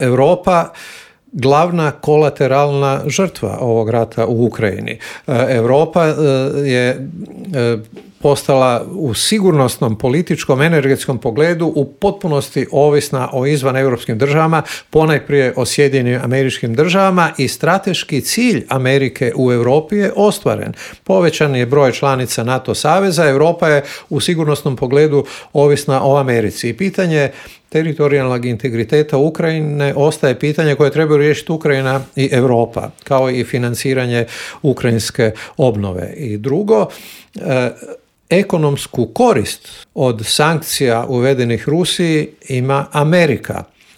Zašto su izbori na Tajvanu prijetnja svjetskom miru i zašto su izgledi za okončanja rata u Ukrajini ove godine mali, pitanja su na koje smo odgovore tražili u intervuju Media servisa s analitičarom Božom Kovačevićem.